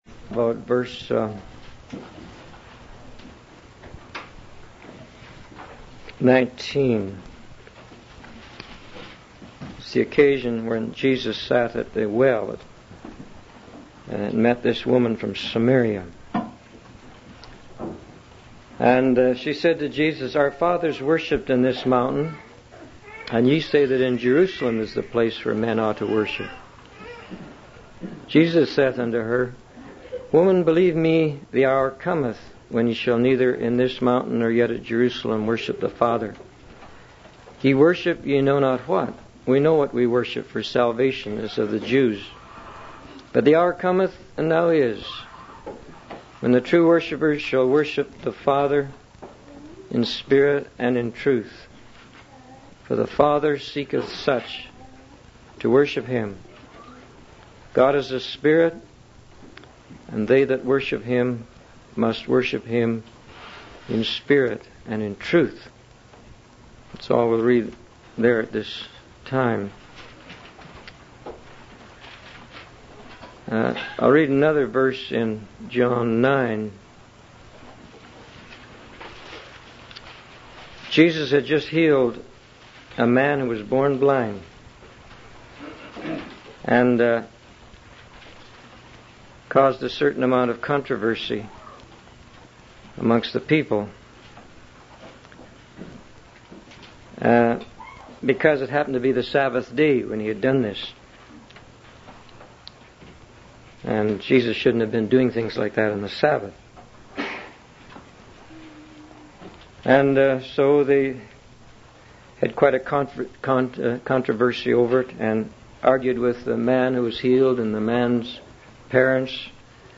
The speaker highlights that God goes out of his way to keep us in line and bring us into tune with him, even if it means using measures that may seem challenging or uncomfortable. Obedience to God's will is emphasized as the highest form of worship, and the speaker encourages the congregation to seek God's will and do it.